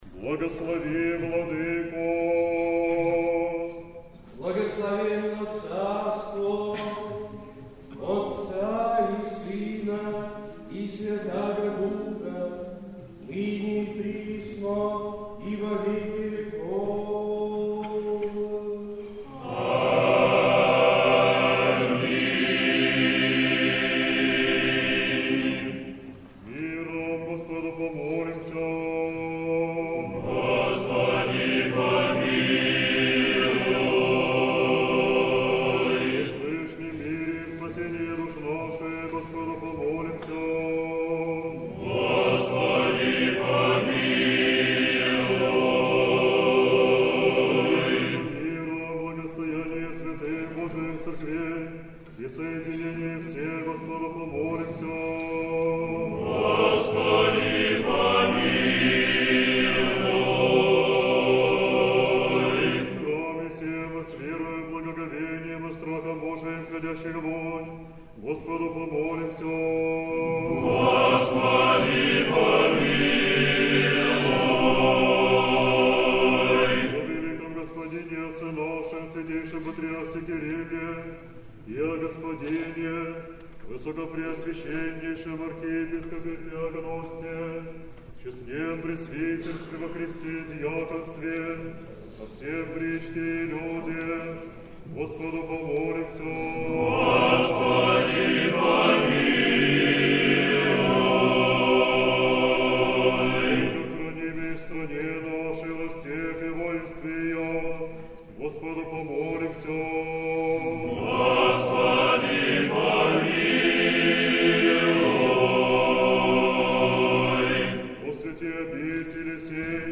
День памяти священномученика Илариона, архиепископа Верейского. Сретенский монастырь. Божественная литургия. Хор Сретенского монастыря.